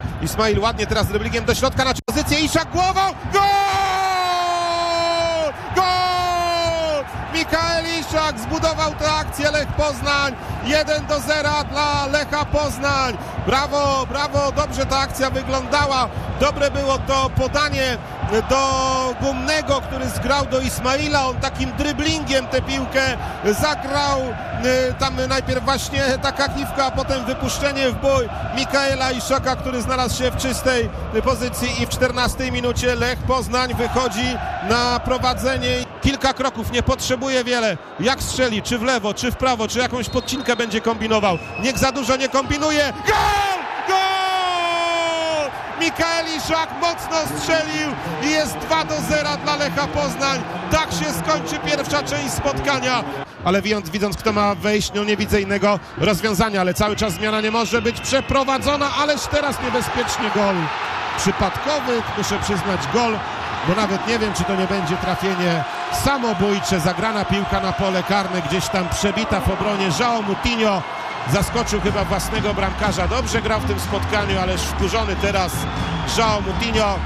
Rewanż w Krakowie